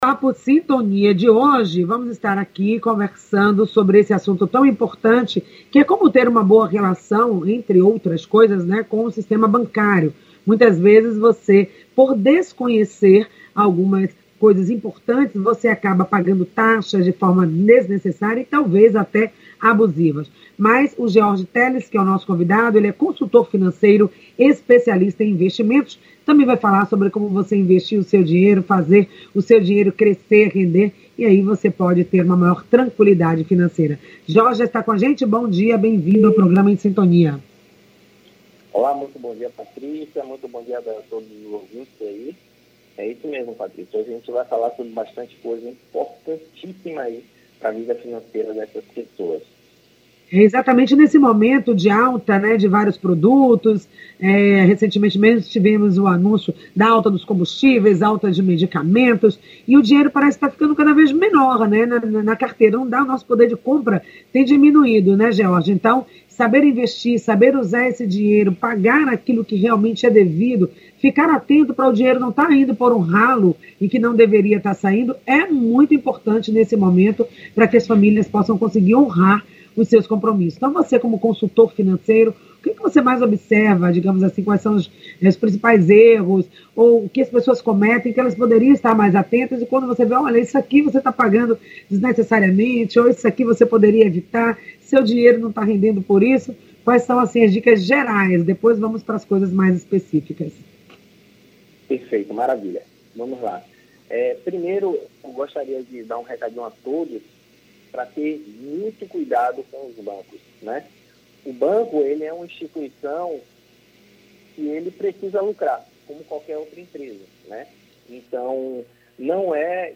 Áudio da entrevista